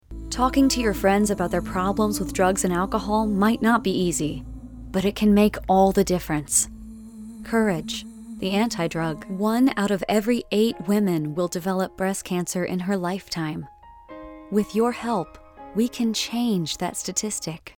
Female Voice Over, Dan Wachs Talent Agency.
Young Mom, Best Friend, Warm & Caring.
PSA